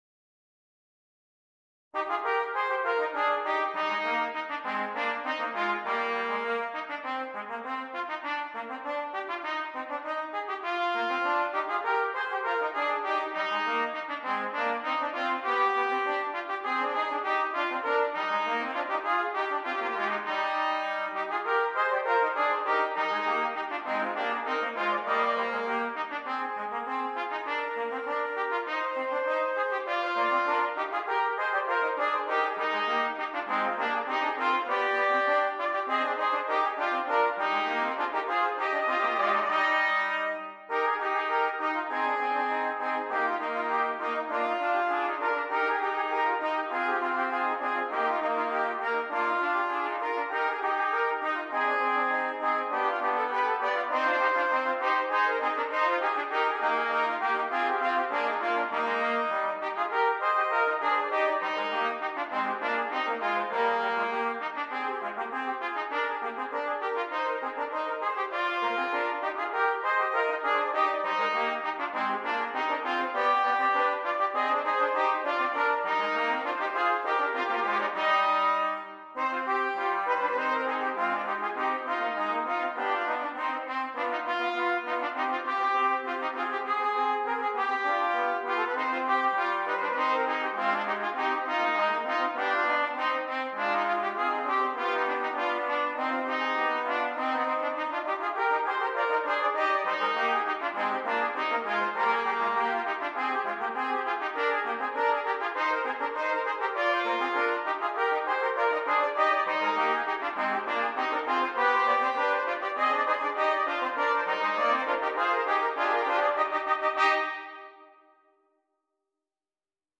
3 Trumpets